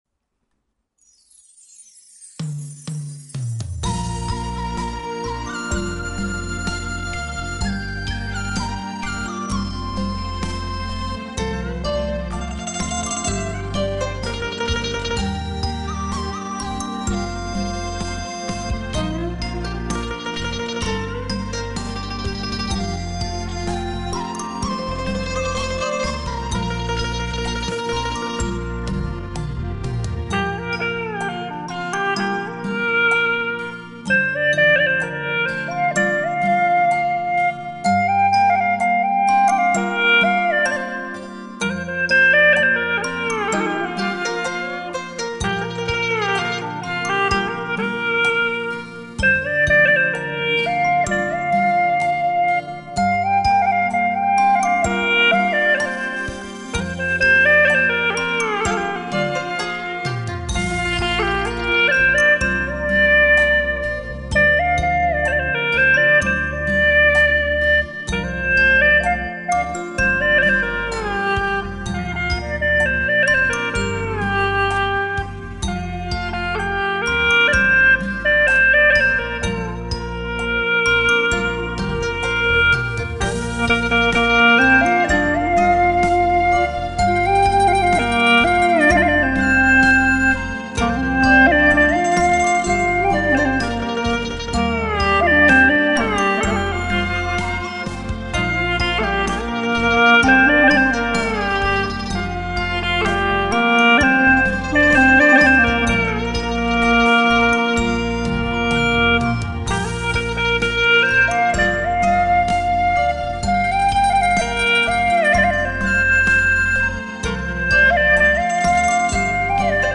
调式 : 降B 曲类 : 流行 我要学习此曲 点击下载 北江，珠江水系干流之一。